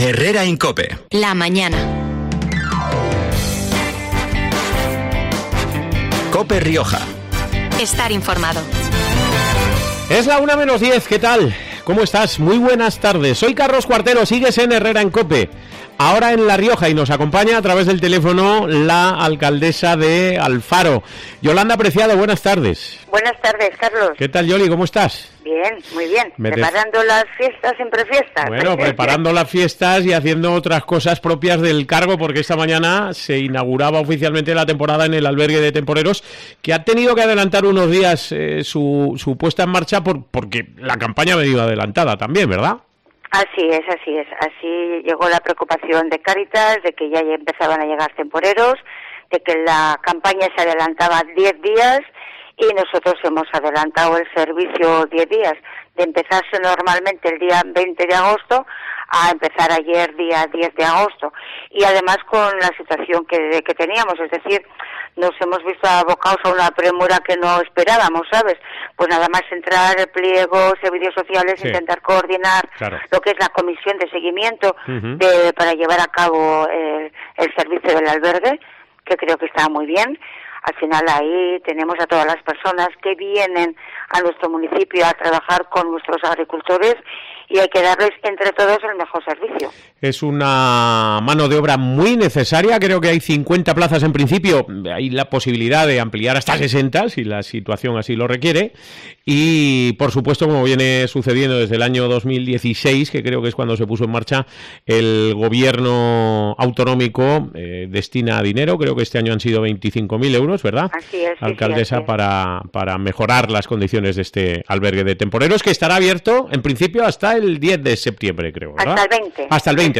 La alcaldesa alfareña, Yolanda Preciado
en COPE Rioja